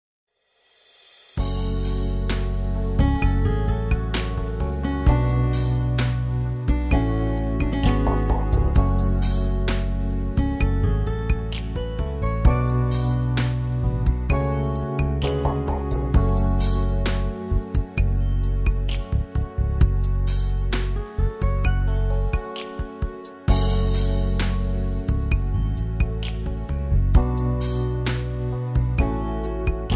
karaoke
dance music